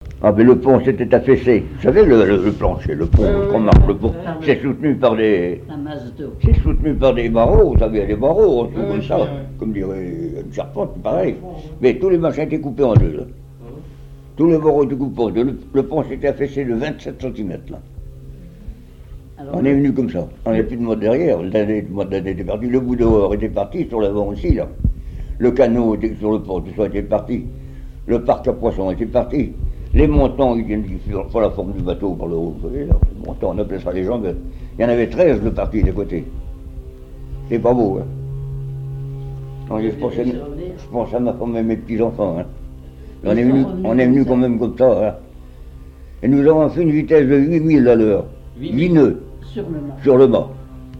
Témoignage du pêcheur de thon et des chansons